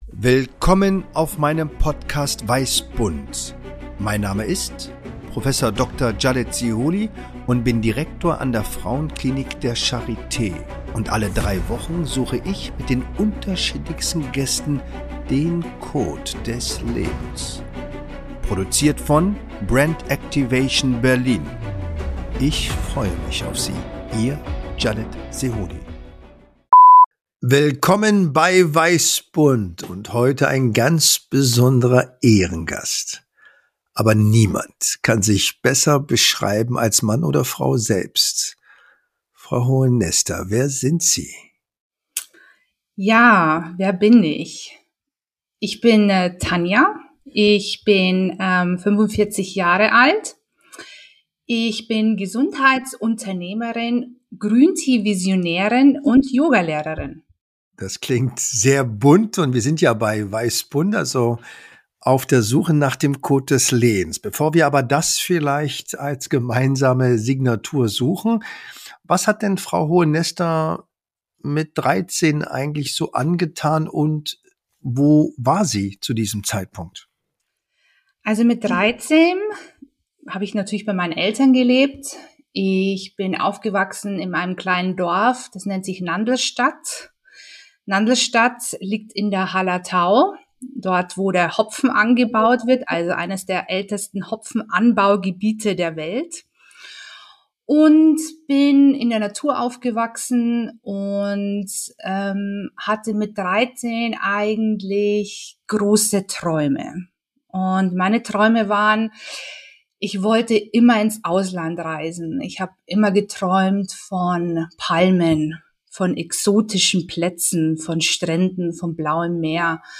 Beschreibung vor 1 Jahr Spontan, intuitiv, ohne Skript, Improvisation pur!